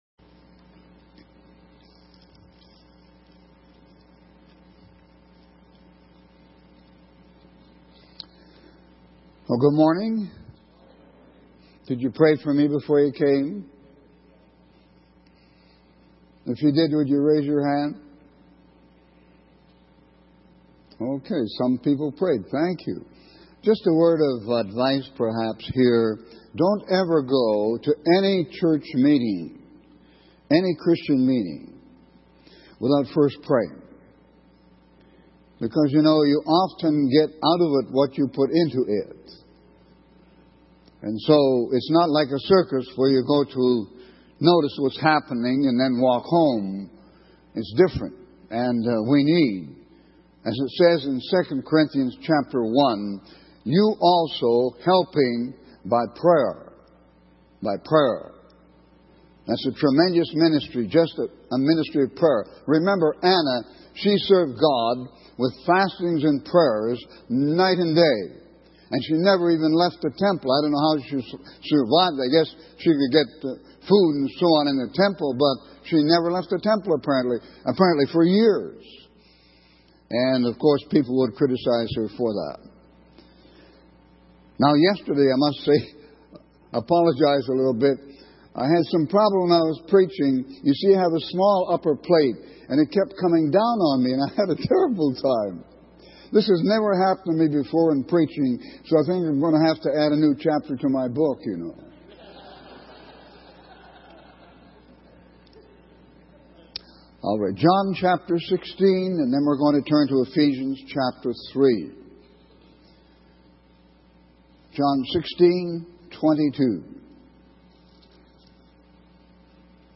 In this sermon, the speaker shares the story of J.B. Earle, a preacher who initially struggled to see any response or conversions in his sermons.